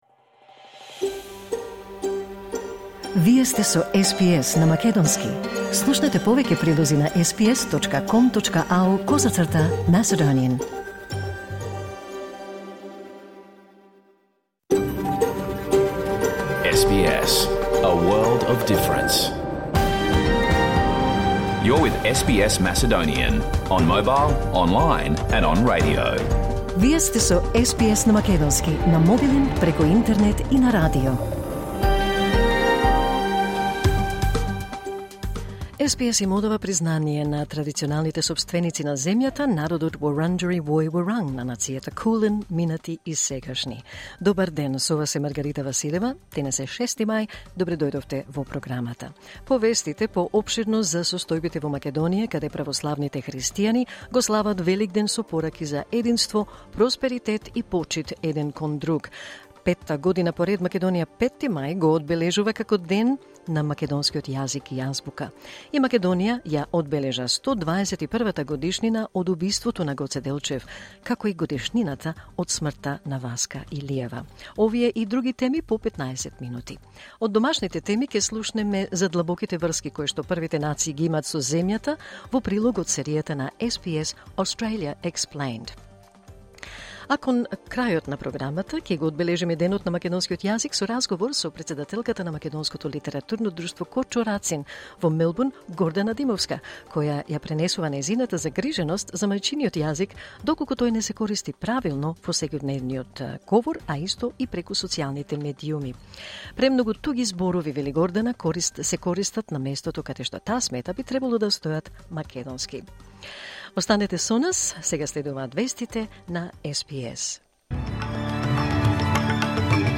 SBS Macedonian Program Live on Air 6 May 2024